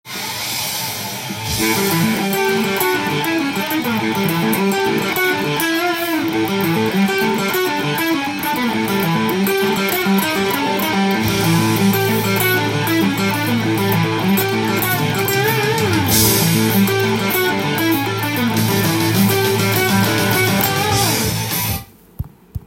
右手が強化できるTAB譜
音源に合わせて譜面通り弾いてみました
ずっとハイポジションで弾き続けるリフになっています。
6弦から3弦まで弦移動を頻繁に繰り返されています。